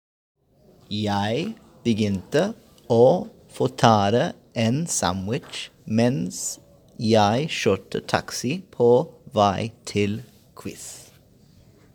Well… Because as always I was curious about their lifestyles here in Norway, so I asked them a few questions and of course I recorded their voices reading that pangram to discover how they pronounce the Norwegian sounds!